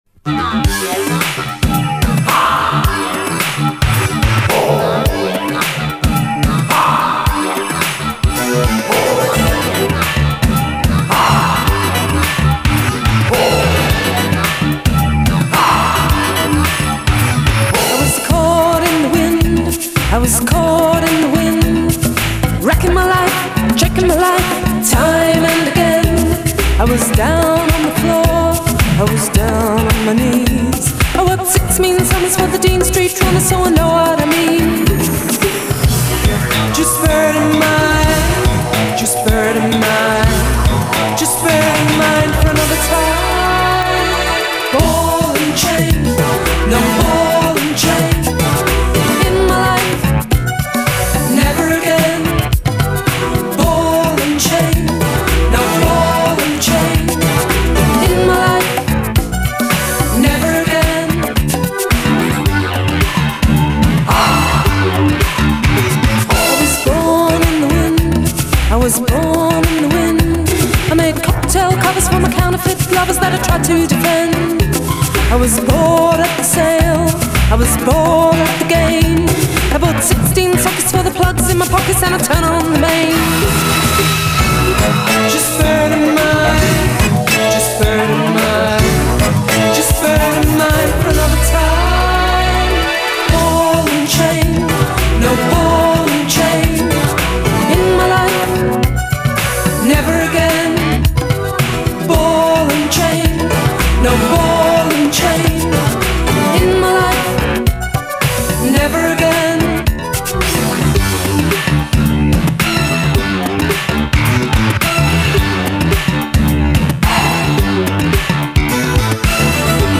Genre: Synthpop.